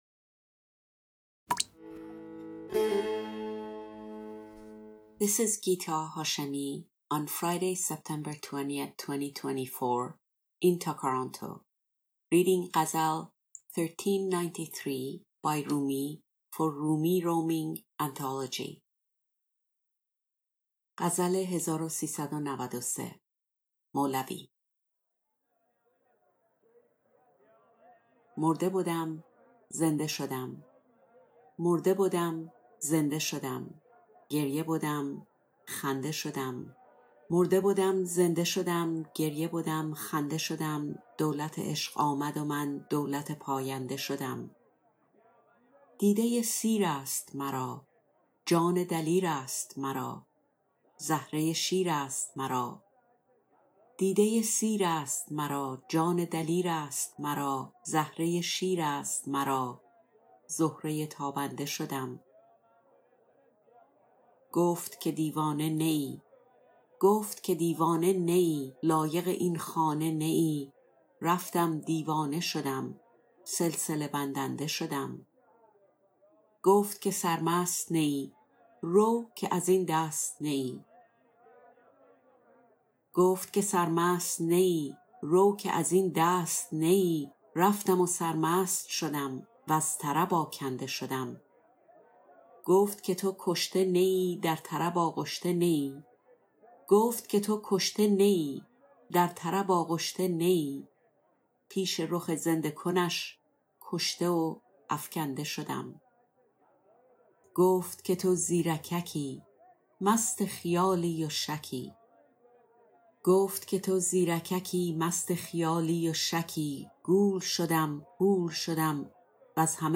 Rumi, Ghazal 1393, Translation, Rumi roaming, Poetry, New love